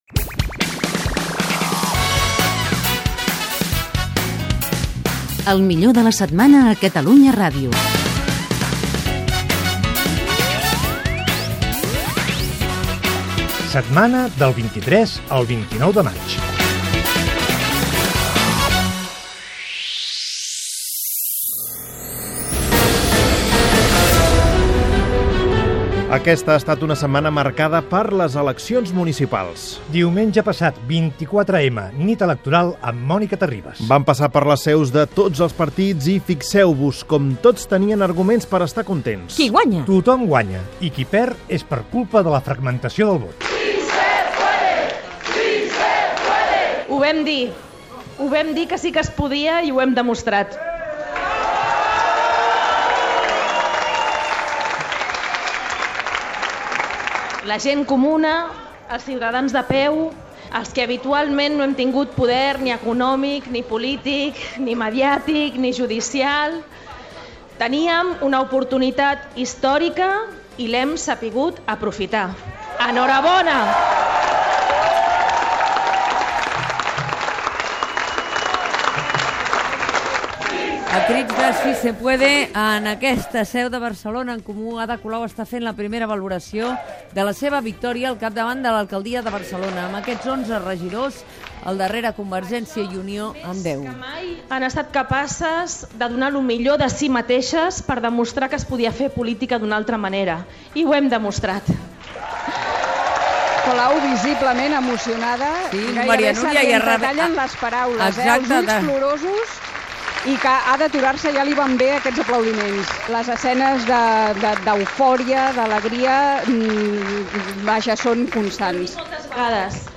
El millor del 23 al 29 de maig del 2015. Careta del programa, dates, la nit de les eleccions municipals de Barcelona, amb declaracions d'Ada Colau de Barcelona en Comú, guanyadora de les eleccions a l'Ajuntament de Barcelona
Info-entreteniment